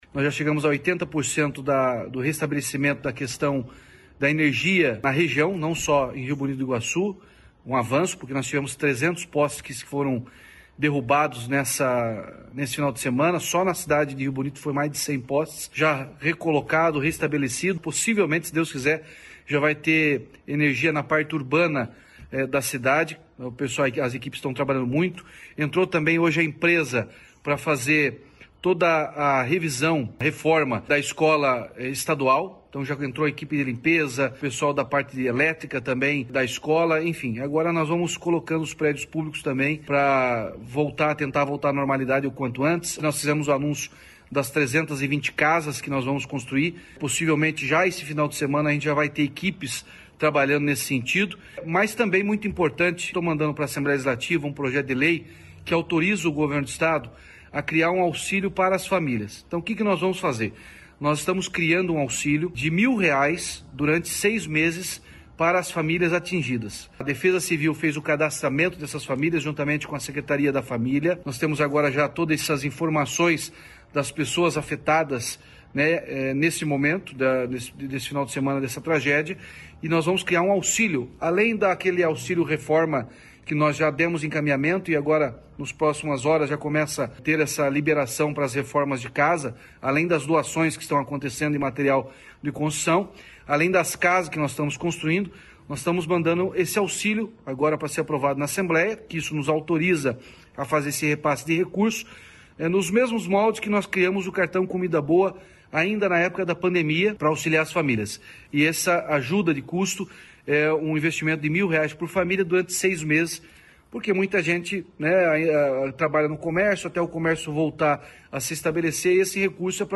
Sonora do governador Ratinho Junior sobre o auxílio mensal para as pessoas afetadas pelas tragédias em Rio Bonito do Iguaçu